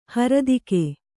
♪ haradike